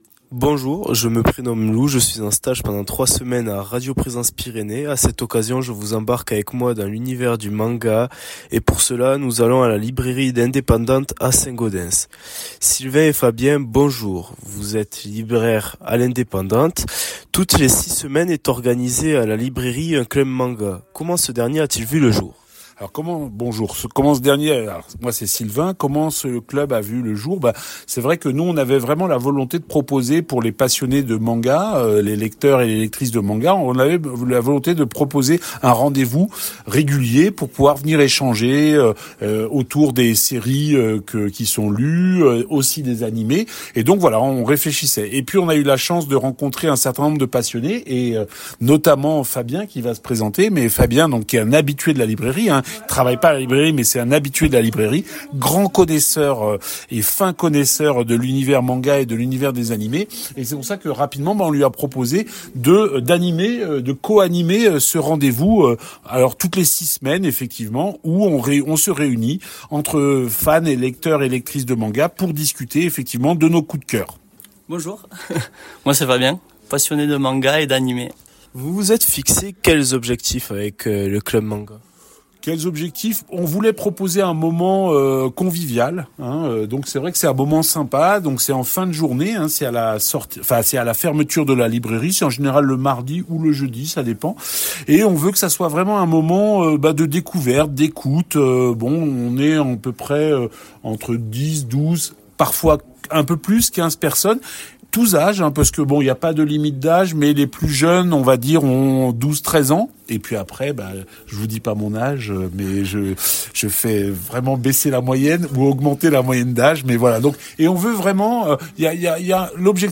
Comminges Interviews du 25 févr.